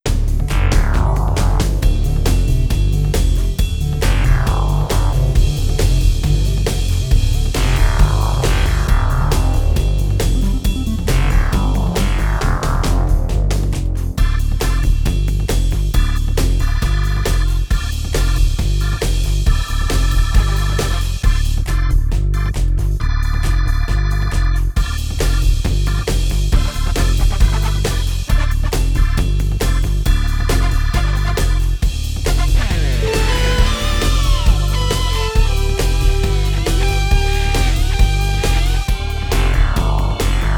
melodía
repetitivo
rítmico
rock
sintetizador
soul